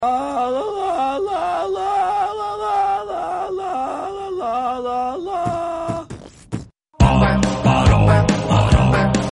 We’re excited to share Furcorn’s new voice!